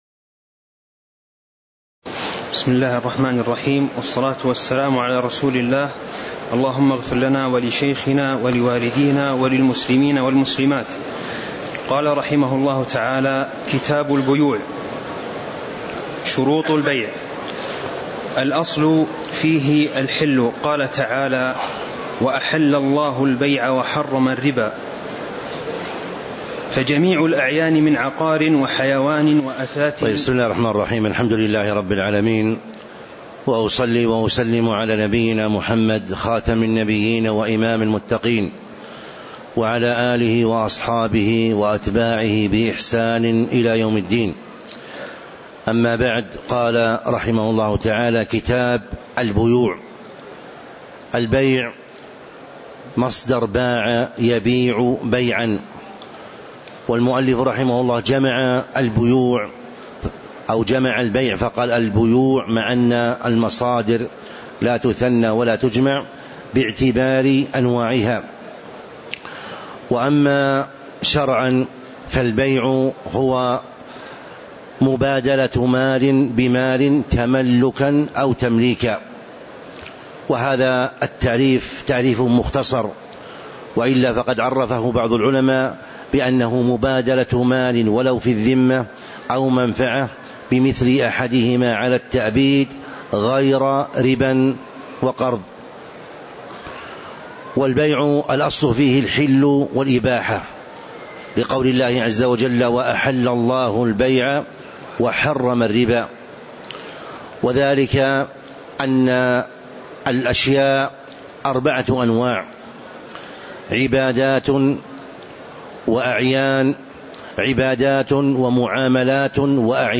تاريخ النشر ١٤ محرم ١٤٤٦ هـ المكان: المسجد النبوي الشيخ